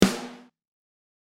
there are two versions, on is dry and the other one is with a room sample blended in for extra KSSSCHHHH.
RF B Rim
It's a Sensitone Elite Custom Alloy snare with a powerstroke head btw.